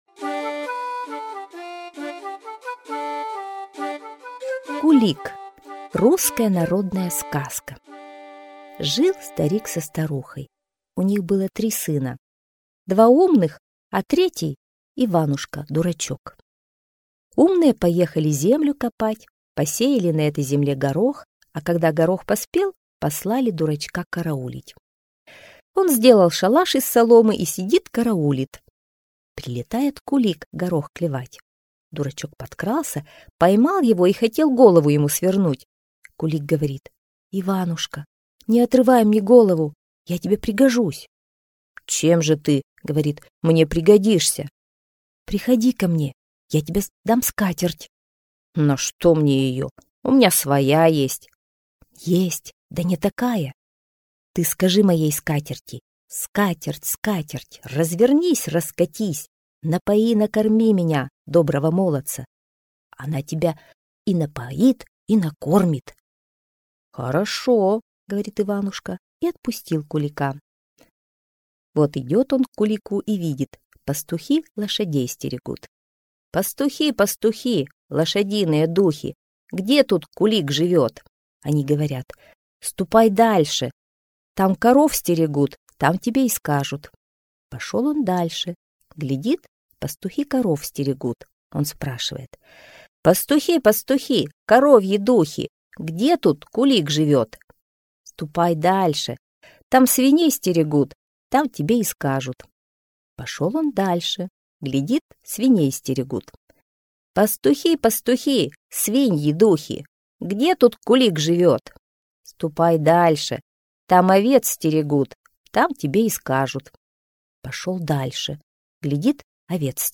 Кулик - русская народная аудиосказка - слушать онлайн